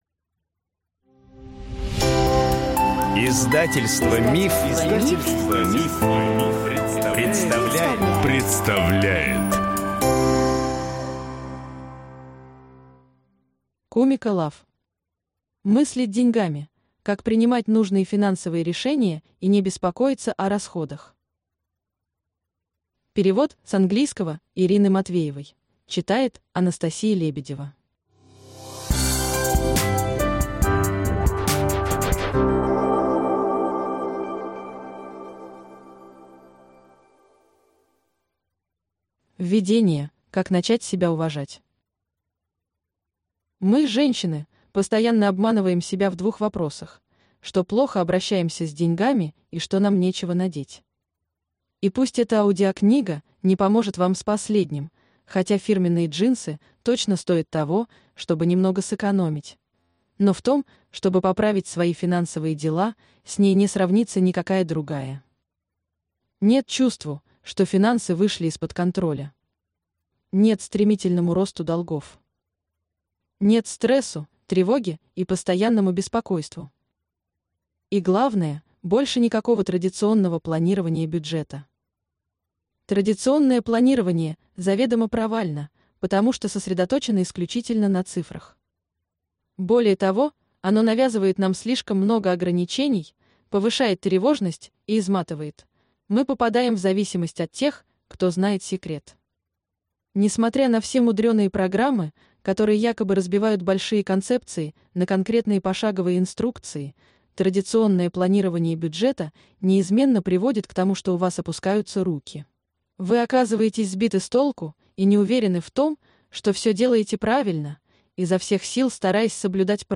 Аудиокнига Мыслить деньгами. Как принимать нужные финансовые решения и не беспокоиться о расходах | Библиотека аудиокниг